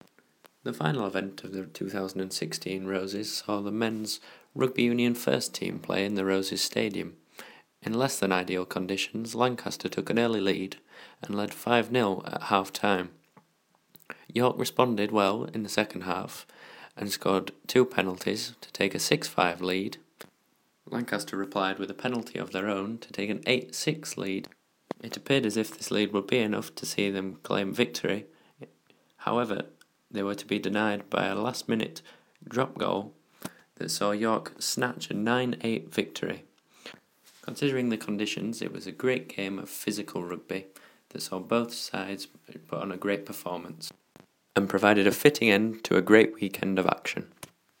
Match Report of the Men's Rugby Union First Team